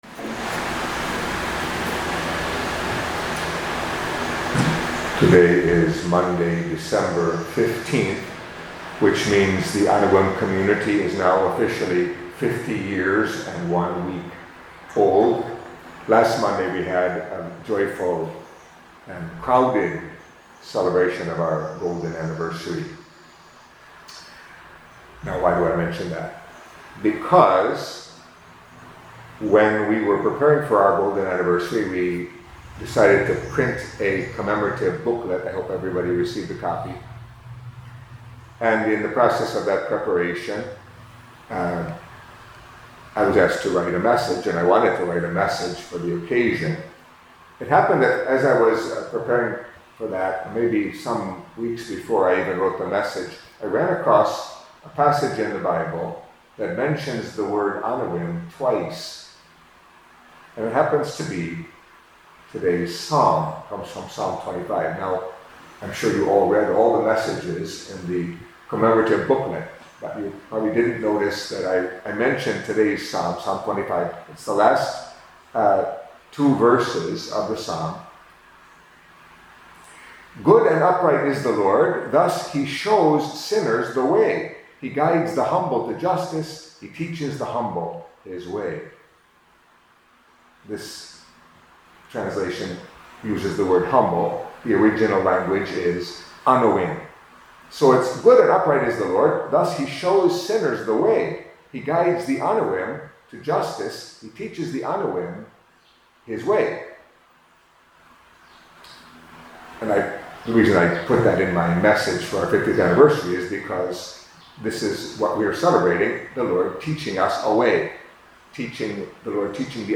Catholic Mass homily for Monday of the Third Week of Advent